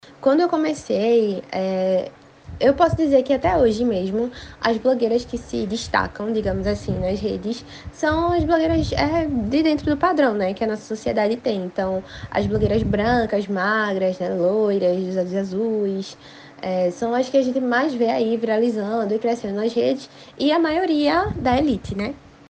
Audio-2-Entrevista-com-blogueira.mp3